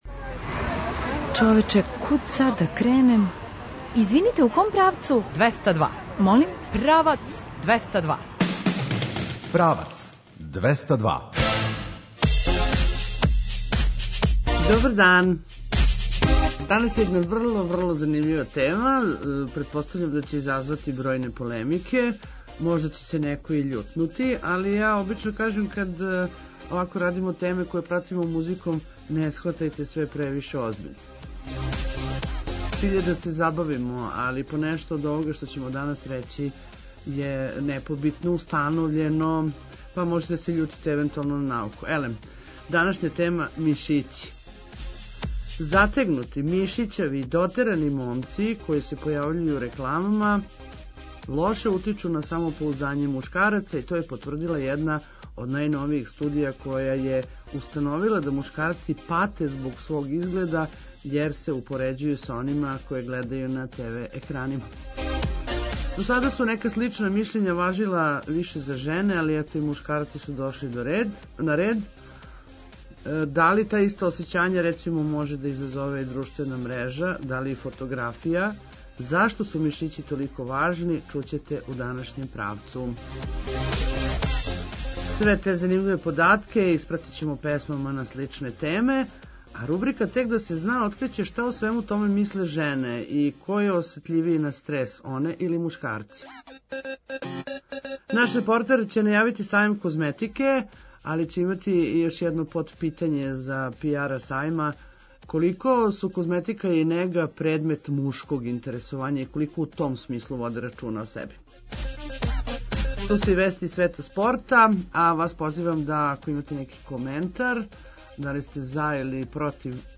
Све те занимљиве податке испратићемо песмама на сличне теме.